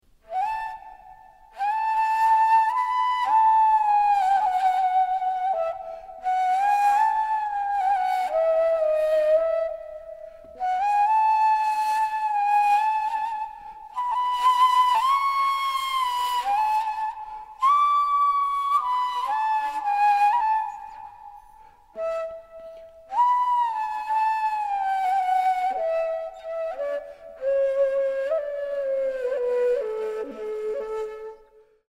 Traditional Turkish Instrument
Audio file of the Ney
Ney001.mp3